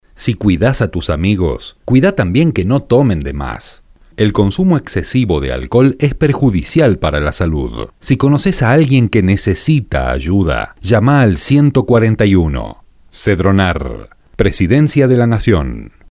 Locutor argentino, español neutro,voz Senior,
Sprechprobe: Industrie (Muttersprache):